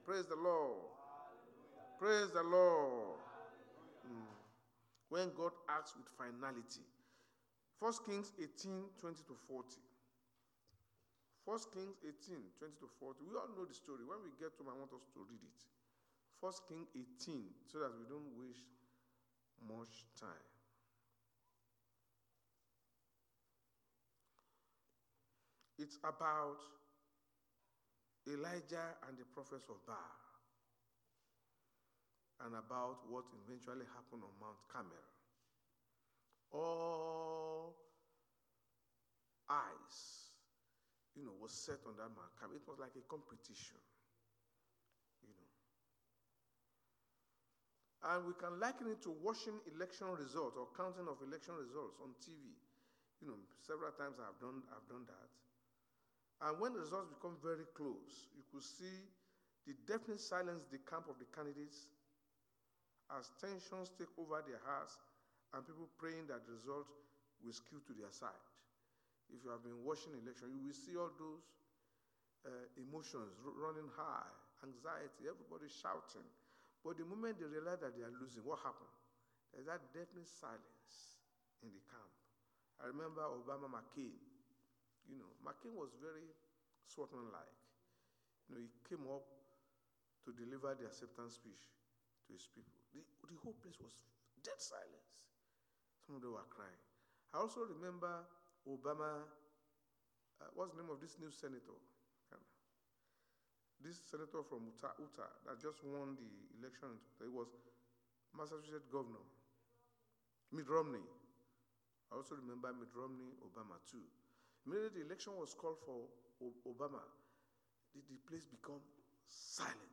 Service Type: Sunday Church Service